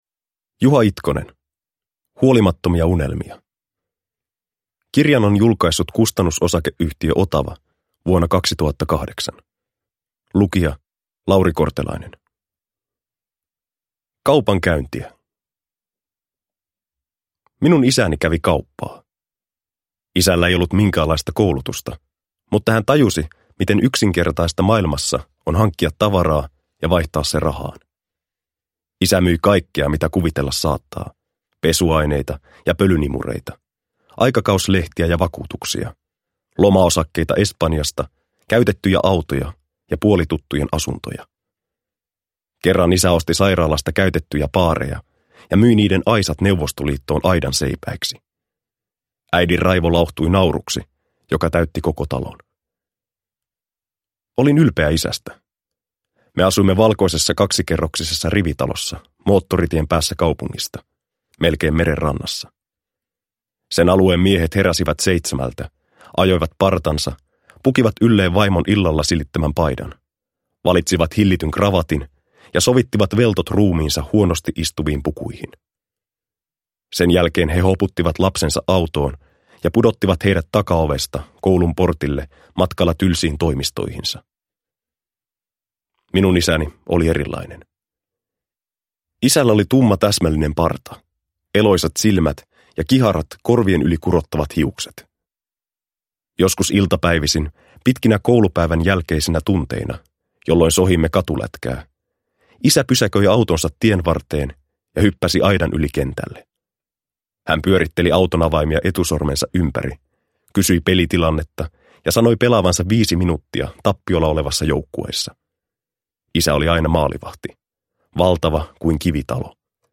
Huolimattomia unelmia – Ljudbok – Laddas ner